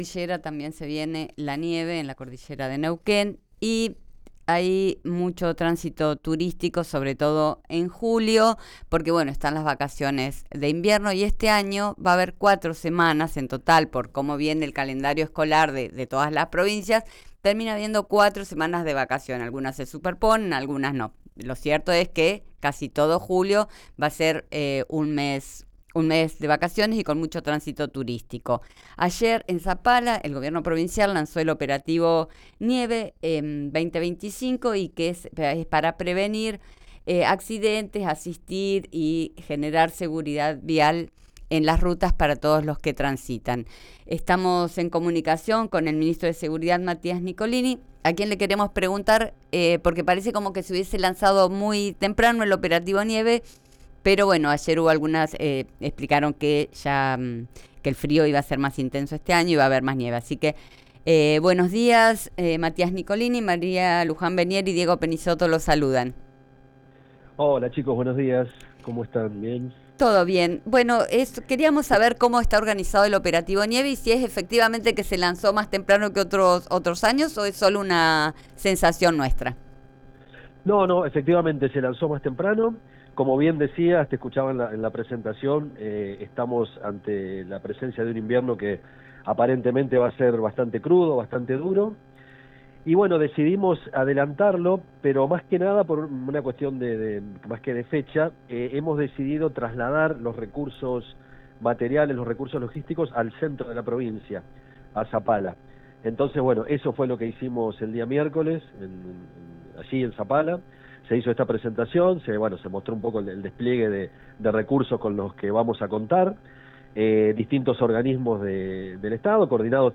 El lanzamiento del Operativo Nieve 2025 se realizó en la ciudad de Zapala durante un acto oficial con horario a las 11.30.